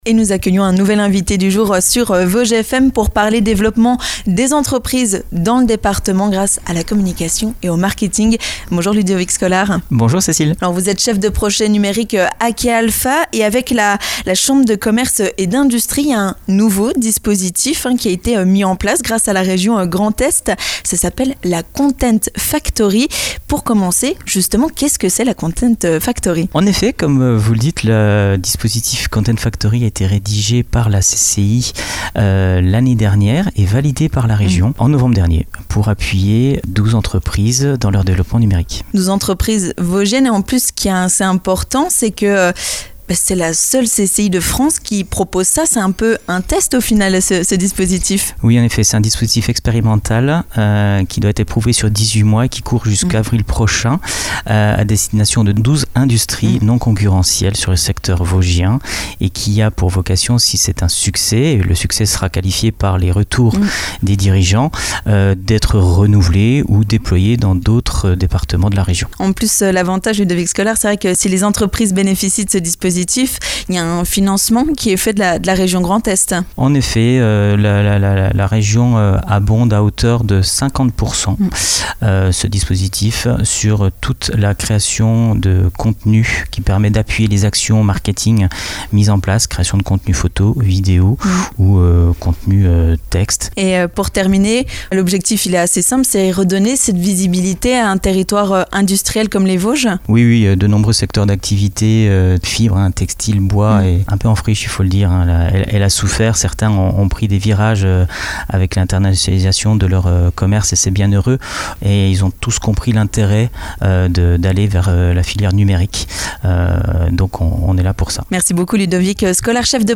L'invité du jour